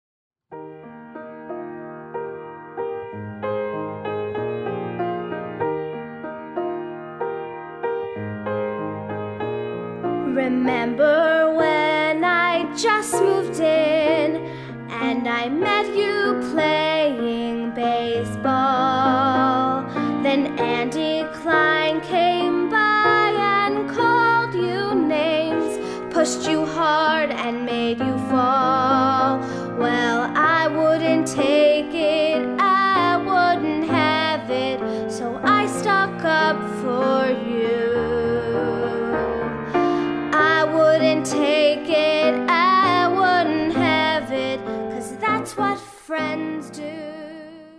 The music was recorded at The Audio Workshop.